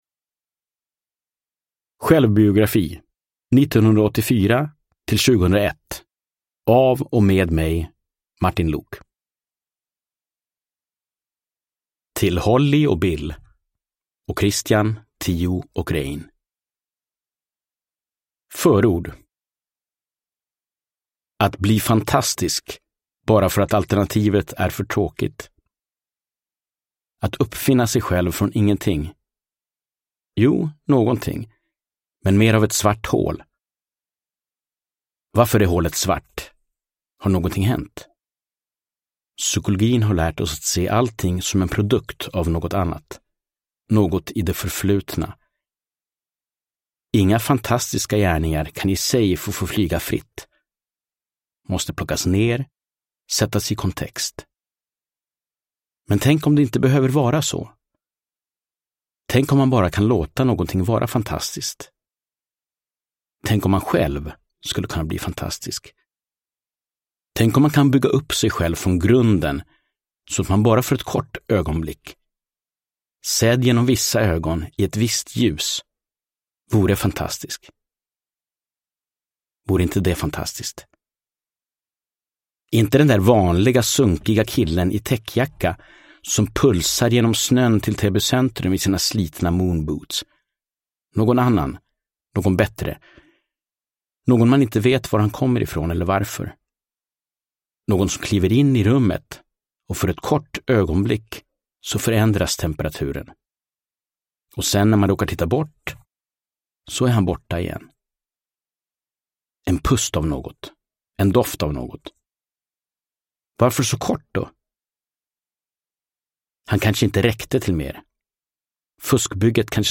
Självbiografi. 1984-2001 (ljudbok) av Martin Luuk